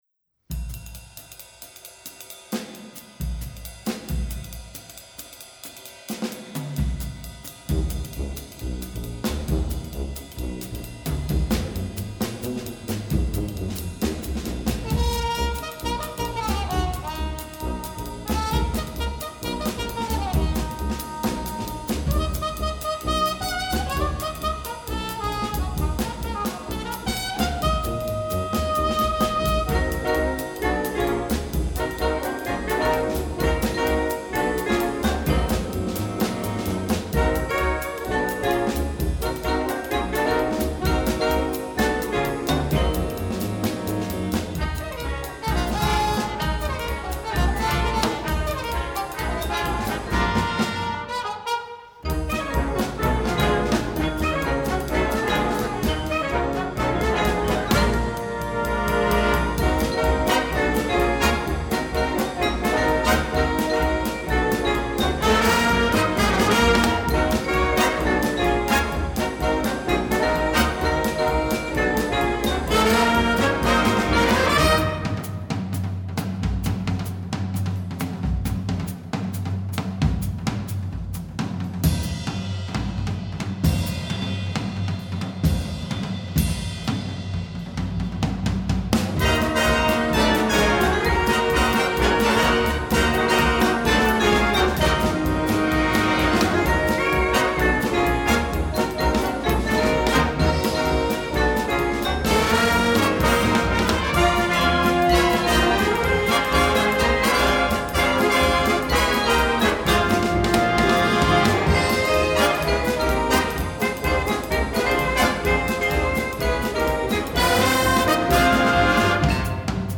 Each movement features an American popular music genre.
Concert Band